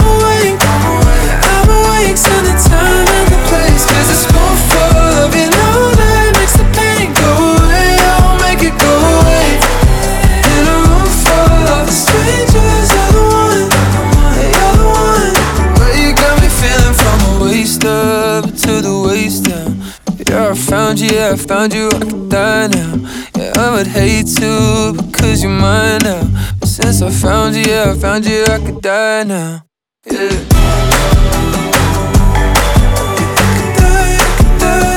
2025-07-17 Жанр: Поп музыка Длительность